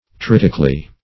-- Trit"ic*al*ly , adv.
tritically.mp3